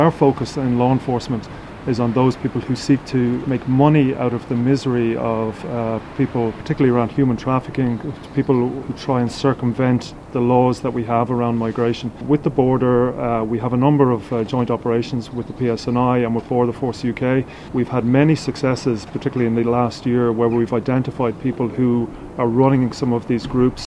He was speaking at a policing conference in County Cavan today, focused on dismantling organised crime gangs across the island.
Garda Commissioner Justin Kelly says innocent people are suffering at the hands of criminals: